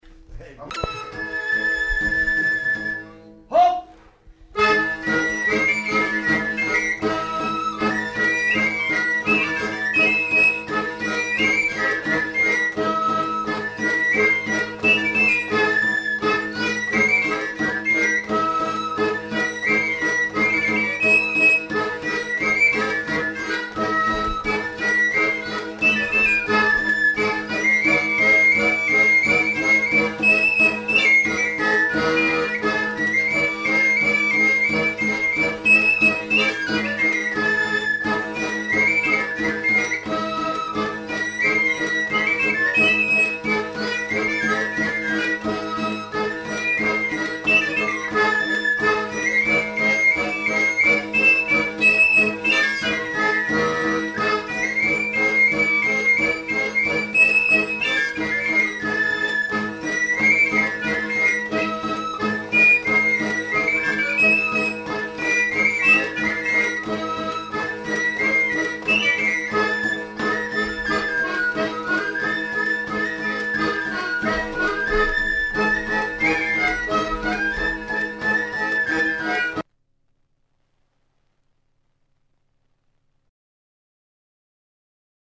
Lieu : Pyrénées-Atlantiques
Genre : morceau instrumental
Instrument de musique : flûte à trois trous ; tambourin à cordes ; accordéon diatonique
Danse : congo
Notes consultables : Il manque la fin du morceau.